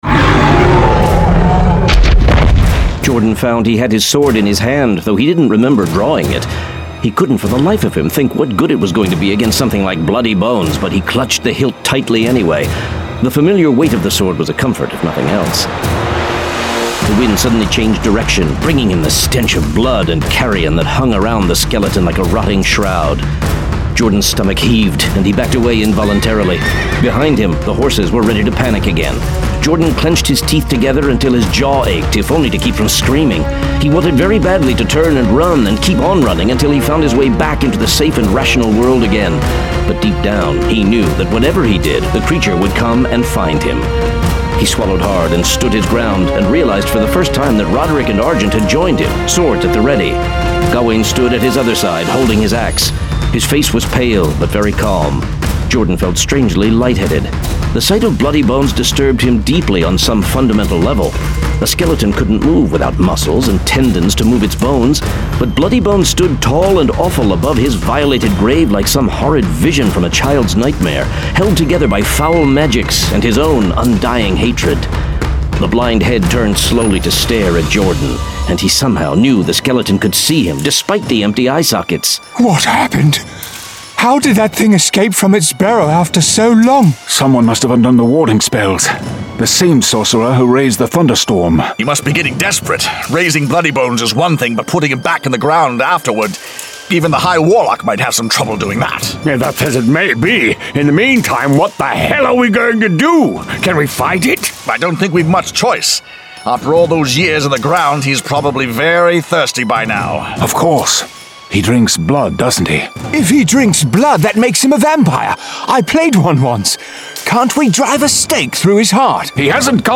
Forest Kingdom Saga: Blood and Honor 1 of 2 [Dramatized Adaptation]